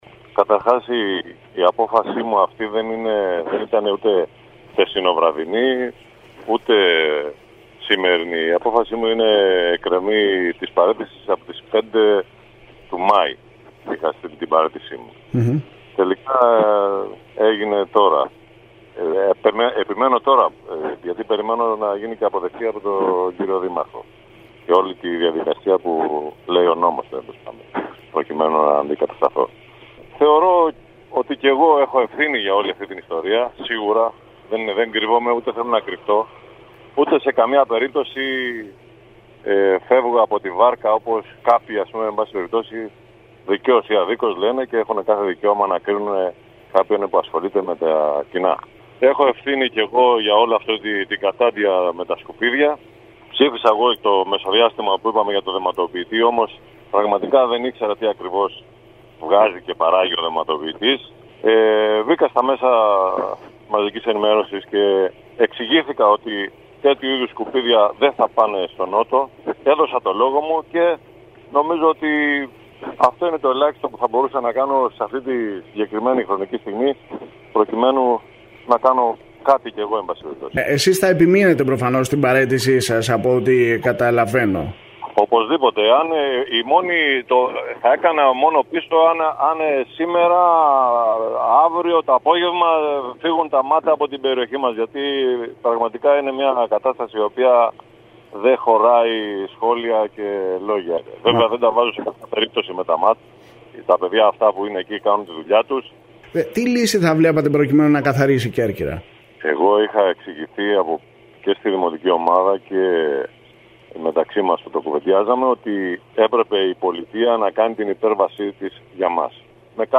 Τους λόγους που τον οδήγησαν στην παραίτηση, εξήγησε ο Δημοτικός Σύμβουλος και Αντιδήμαρχος Νότου, Αλέξανδρος Ασπιώτης, μιλώντας την ΕΡΤ Κέρκυρας. Ο κ. Ασπιώτης ανέφερε ότι η παραίτησή του ήταν στα χέρια του δημάρχου από τον προηγούμενο Μάιο και ότι επανήλθε σε αυτήν εξαιτίας των γεγονότων στη Λευκίμμη.
Ακούστε απόσπασμα των δηλώσεων του κ. Α. Ασππιωτη στον σύνδεσμο που ακολουθεί: